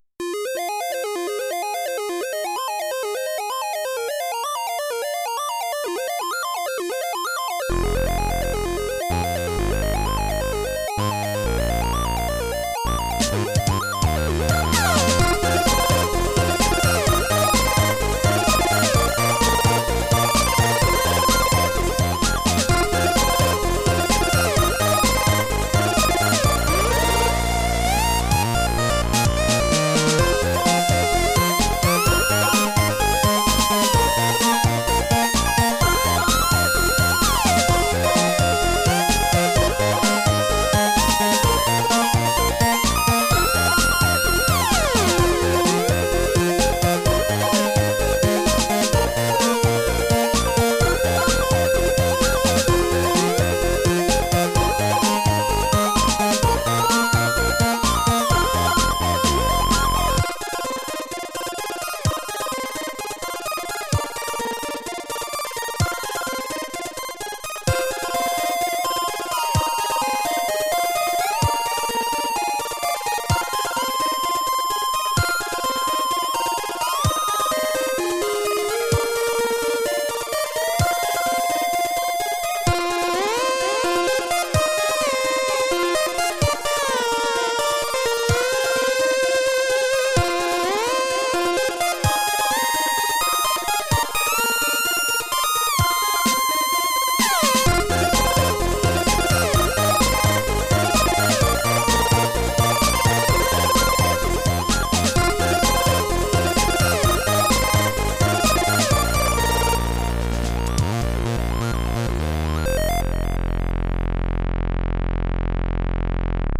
• Categoria: Keygen Music